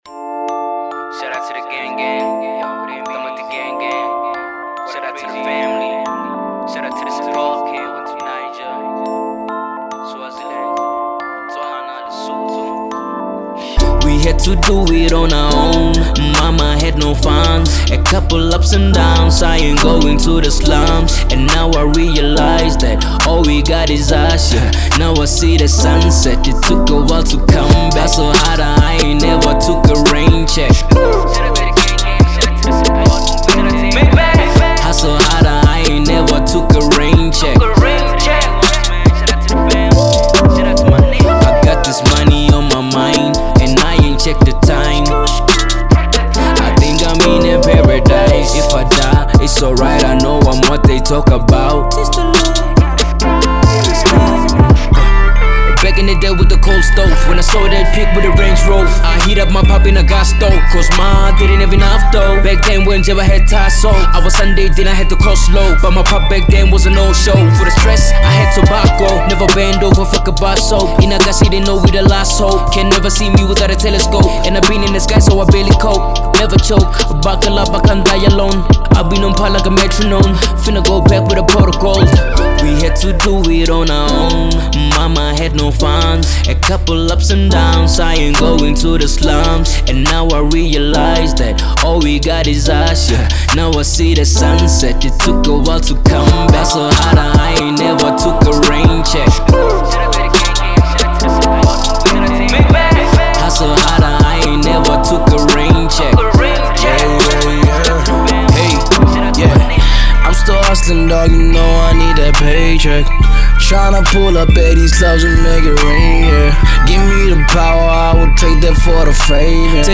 Update your hip-hop playlist immediately.